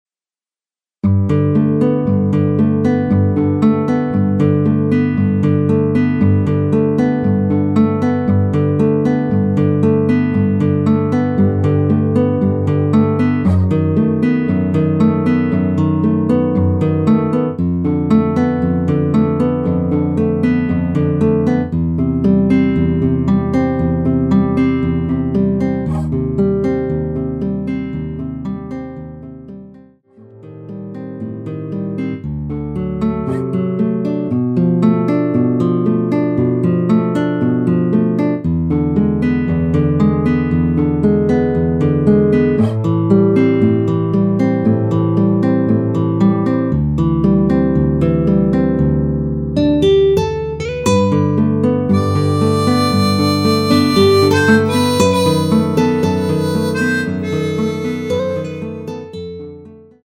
노래방에서 음정올림 내림 누른 숫자와 같습니다.
앞부분30초, 뒷부분30초씩 편집해서 올려 드리고 있습니다.
중간에 음이 끈어지고 다시 나오는 이유는